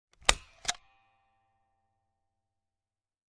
Звуки степлера
Звук скрепки